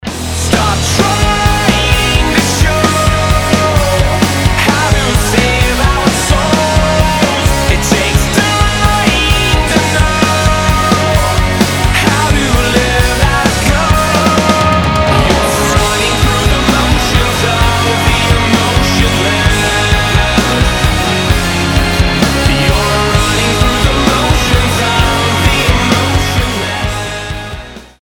• Качество: 320, Stereo
мужской вокал
громкие
красивый мужской голос
Alternative Metal
Alternative Rock
Progressive Metal